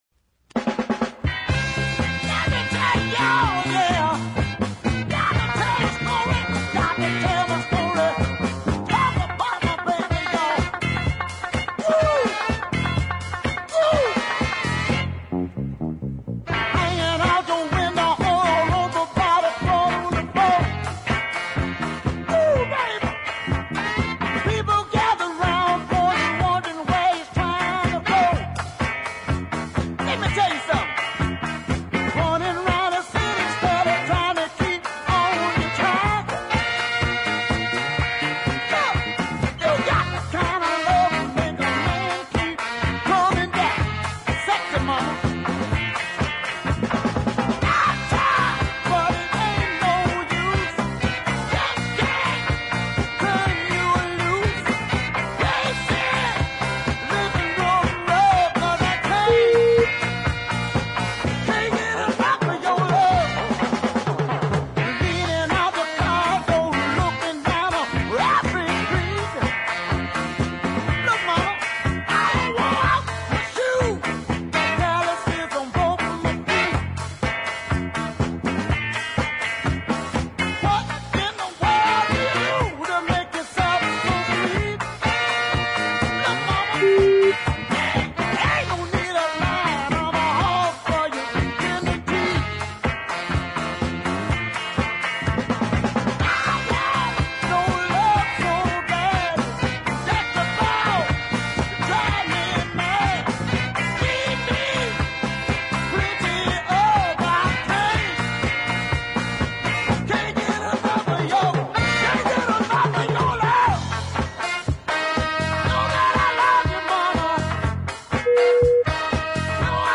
high octane singing
the slight disco beat from the drummer aside